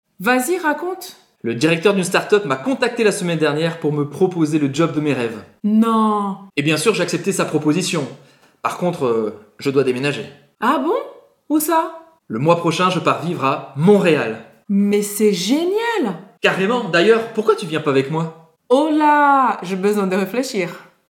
Baixe o áudio lento